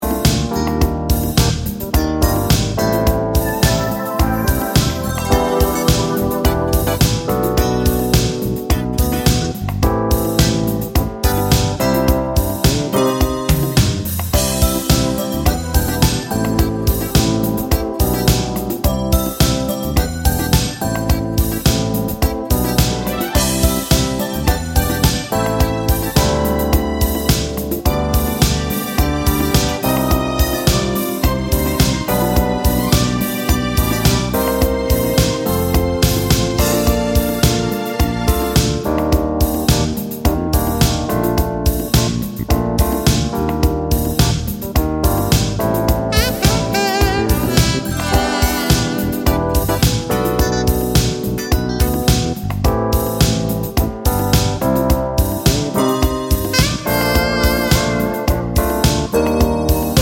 no Backing Vocals Duets 4:23 Buy £1.50